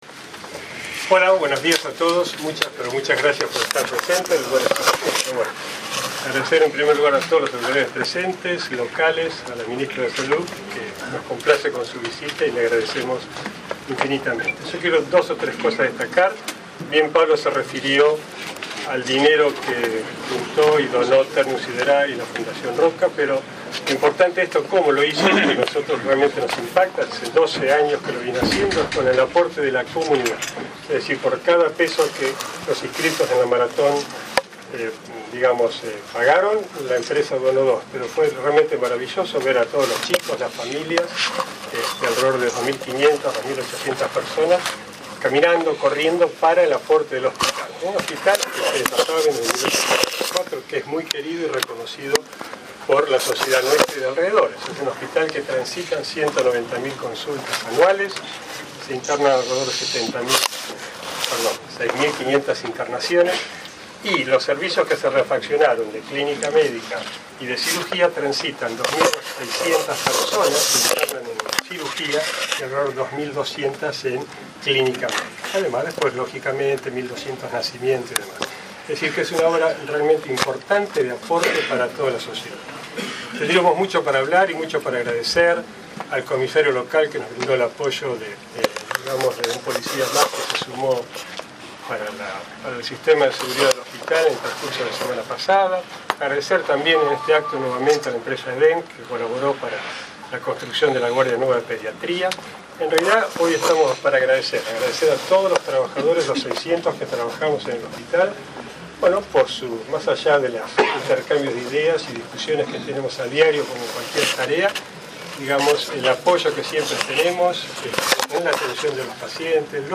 Se realizó un descubrimiento de placa y recorrida de las instalaciones con motivo de la finalización de las obras de puesta en valor del Servicio de Clínica y de Cirugía del Hospital, llevadas a cabo gracias al aporte solidario de la Maratón 10K Ternium realizada en octubre del año pasado.